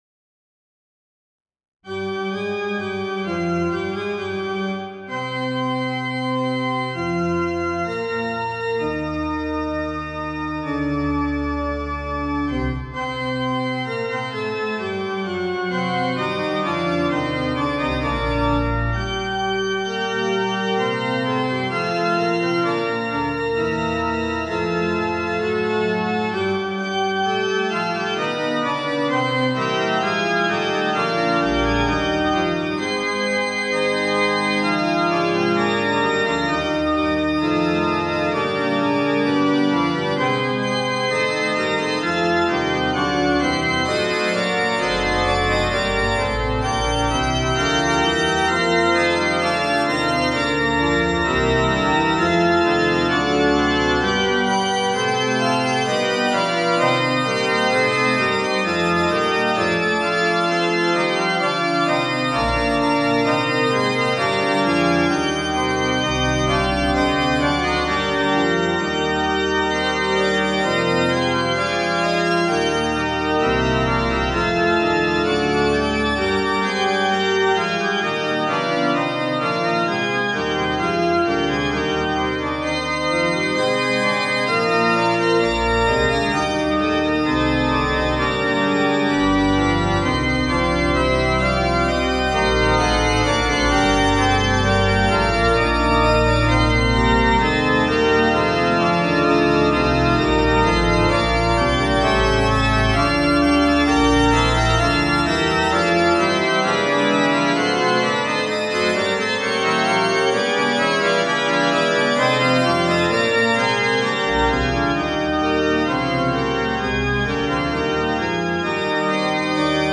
学習フーガ
和声付けに変化があって、形式感のある、聴き飽きないフーガです。